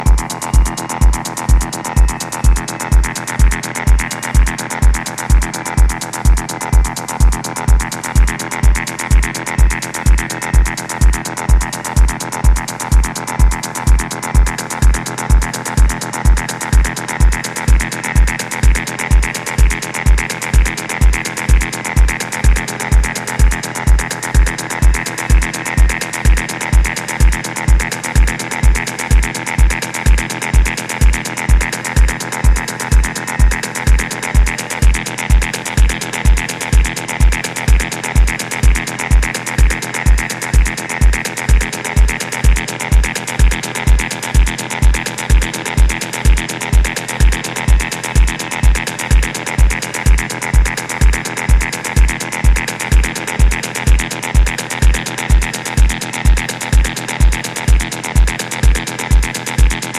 three atmospheric peak-time techno tracks, an ambient piece
Techno